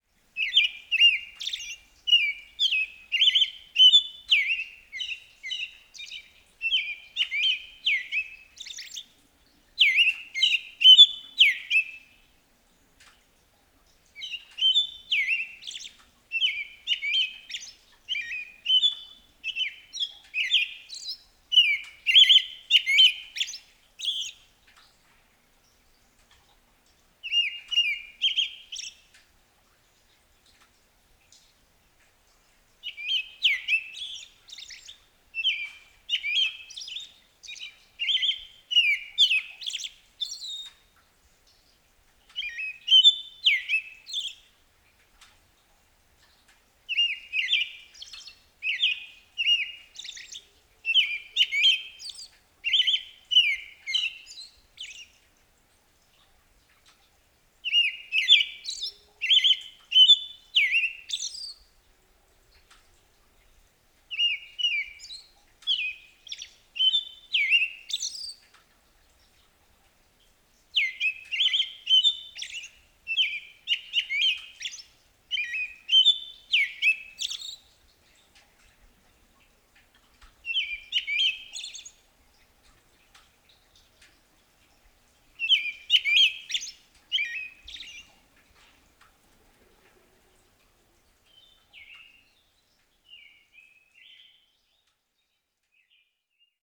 American Robin
Turdus migratorius